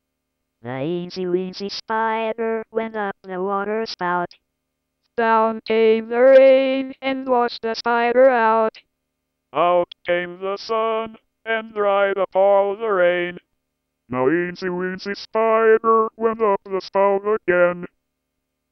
I spent hours composing weird robot jingles.